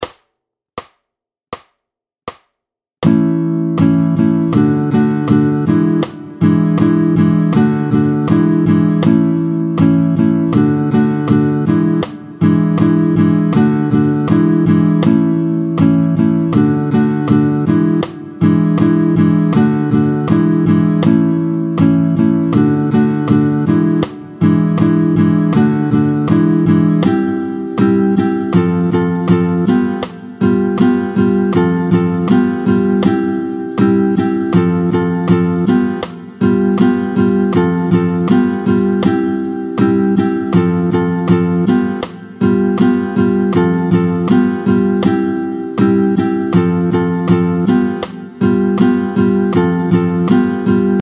10-01 La bamba (Richie Valens), tonalités de La et Do, tempo 80